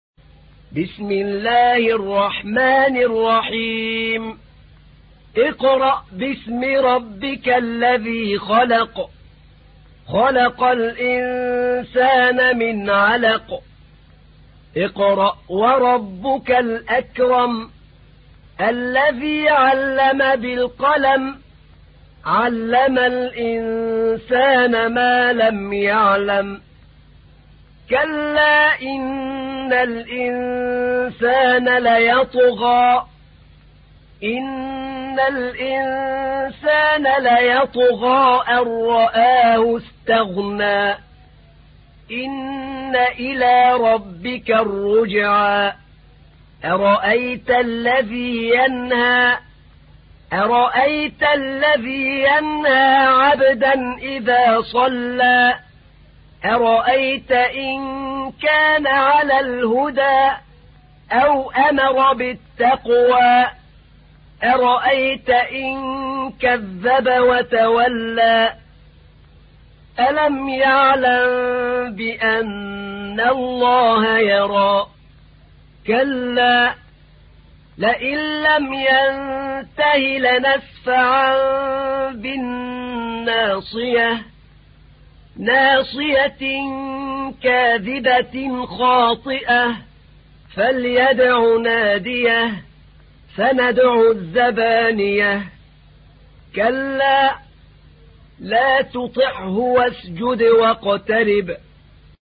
تحميل : 96. سورة العلق / القارئ أحمد نعينع / القرآن الكريم / موقع يا حسين